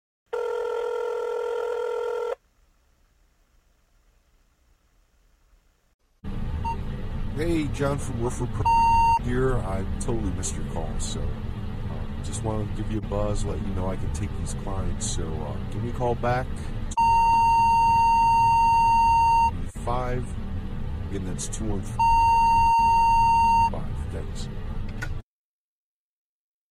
The Voice Lead Generator drops your promo message right into voicemail boxes, no cold calling needed!